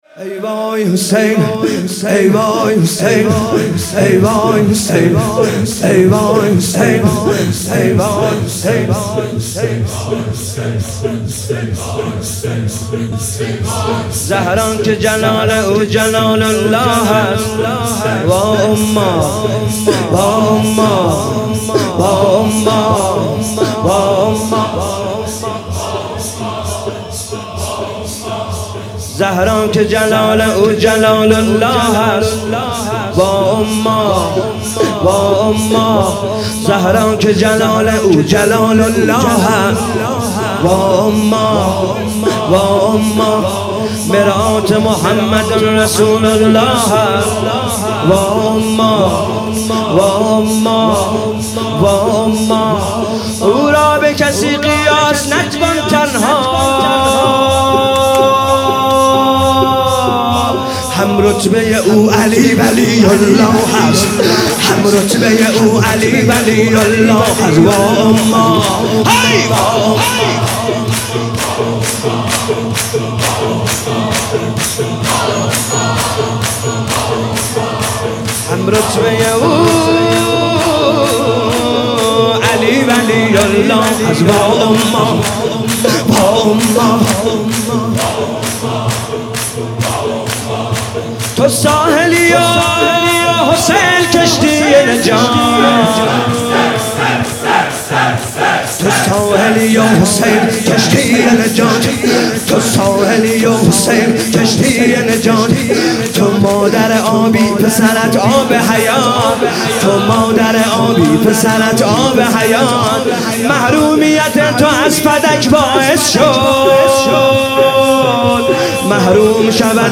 فاطمیه96 - مسجدالهادی(ع) - شب اول - شور - زهرا که جلال او